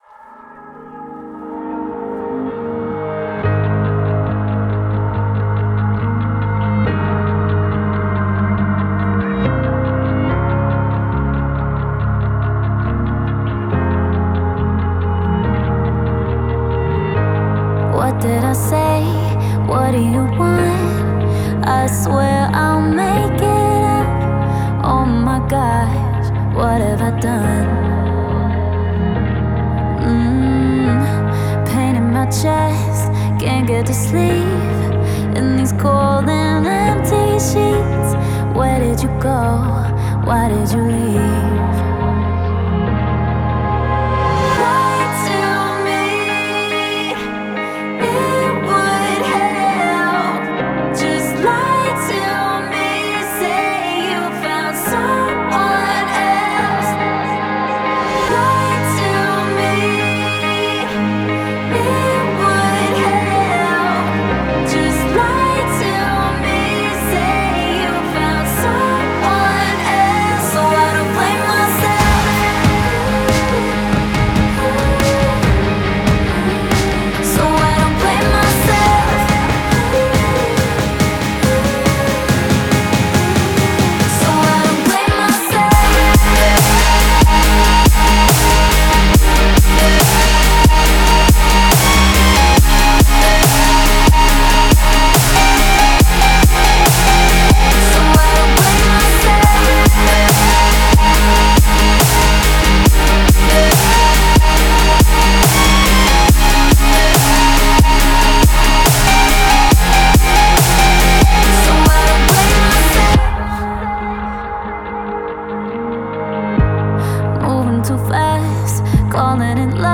это трек в жанре EDM
мощные электронные биты и мелодичный вокал